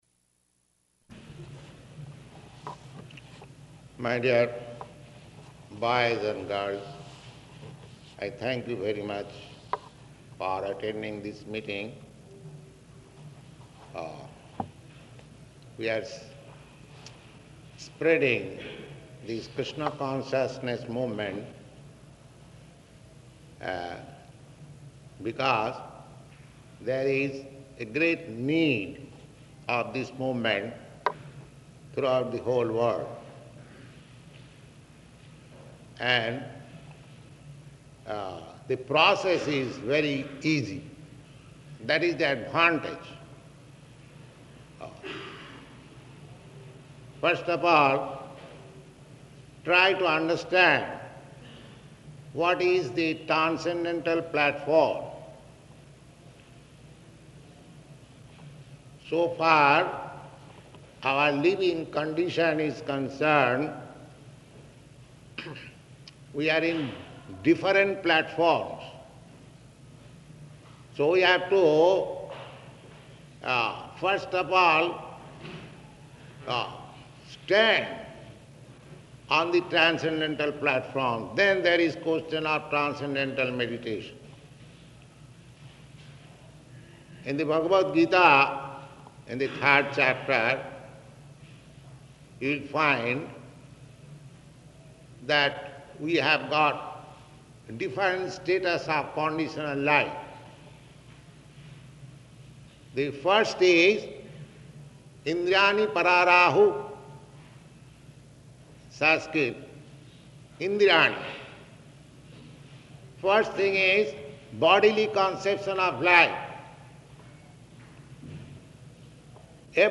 Northeastern University Lecture
Type: Lectures and Addresses